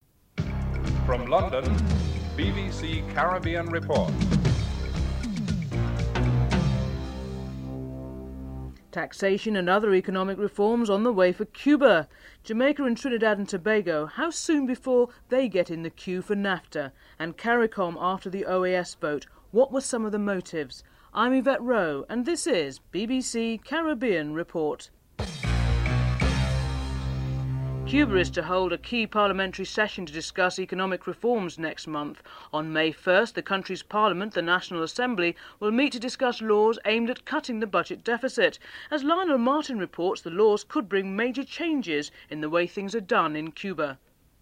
10. Recap of headlines (14:23-14:37)
11. Theme music (14:38-14:52)